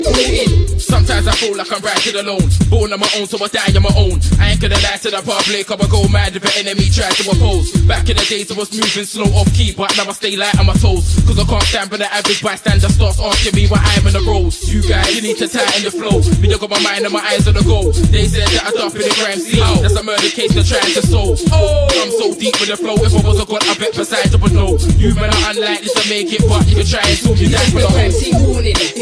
Animals sounds